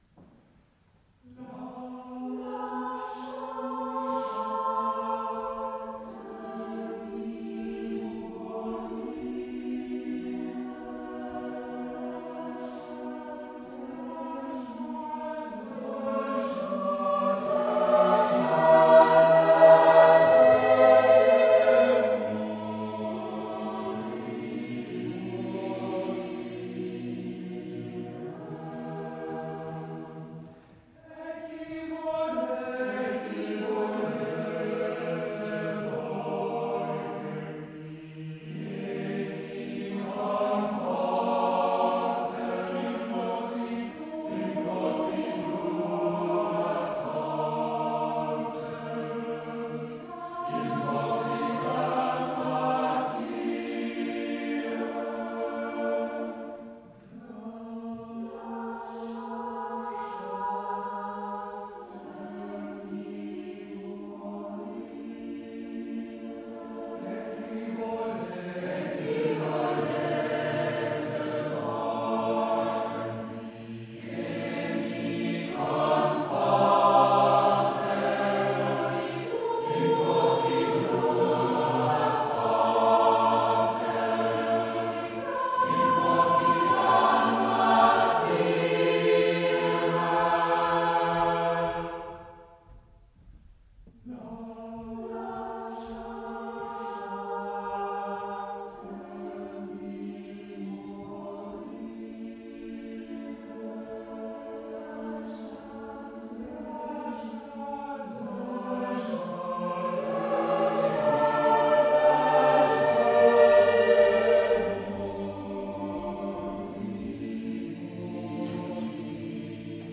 Lasciate mi morire uniChor 03.06.2005 Groningen